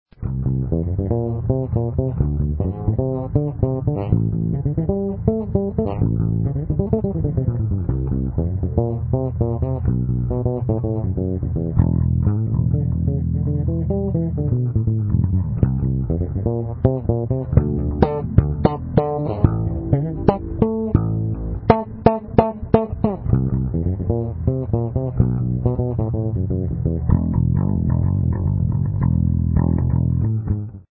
Файл BASS.mp3
Даже слэп получился.